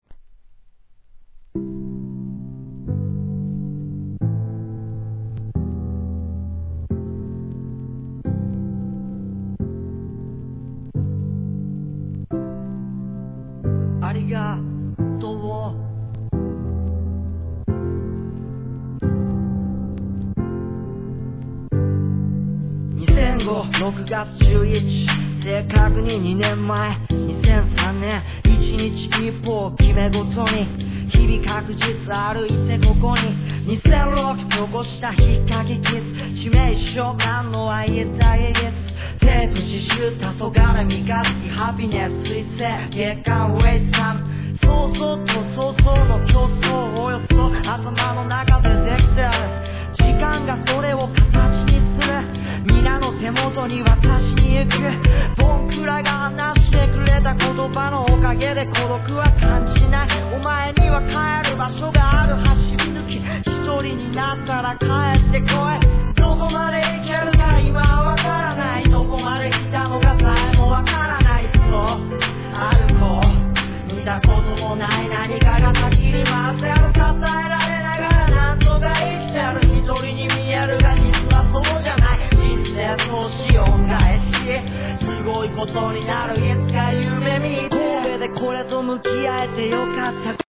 1. JAPANESE HIP HOP/R&B